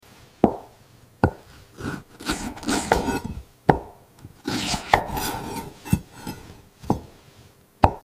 Precision meets purity — slicing sound effects free download
Precision meets purity — slicing through a white eggplant like butter.